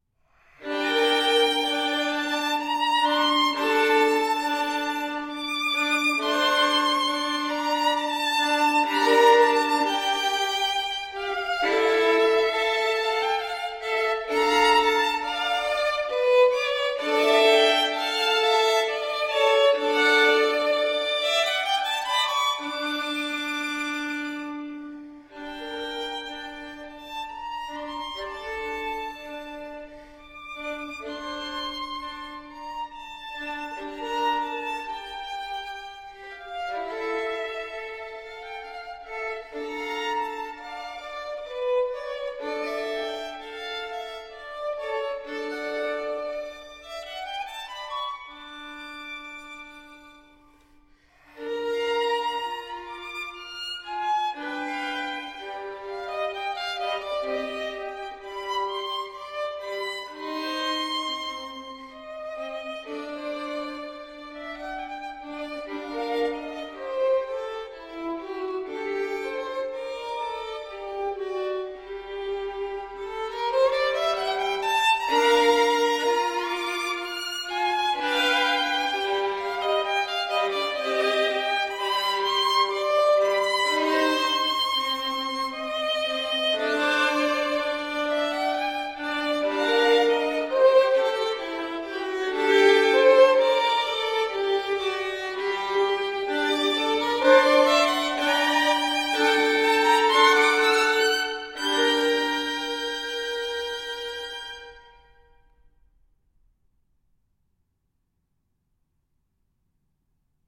Violine